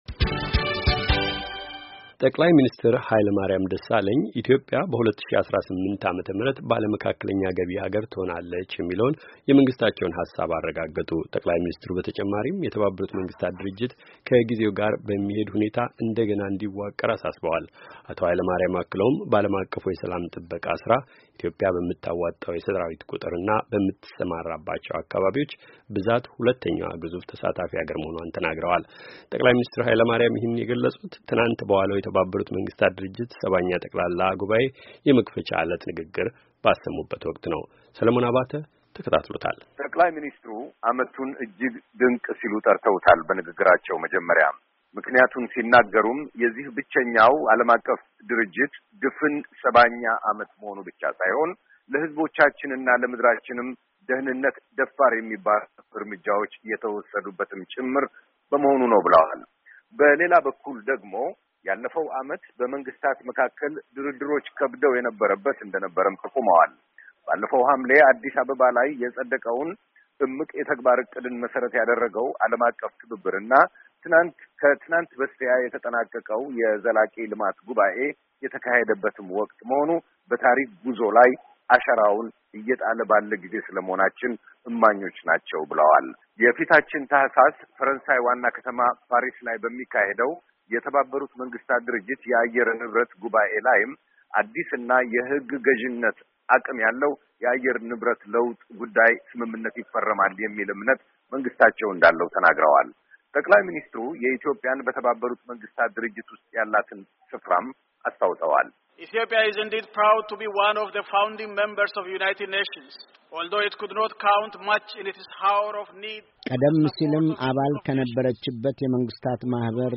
የኢትዮጵያ ጠቅላይ ሚኒስትር የተባበሩት መንግስታት ሰባኛ ጉባኤ ንግግር